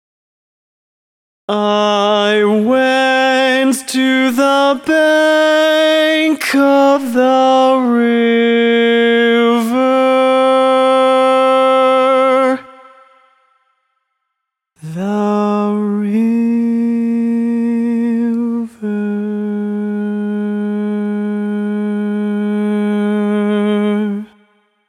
Key written in: A♭ Major
Type: Barbershop
Each recording below is single part only.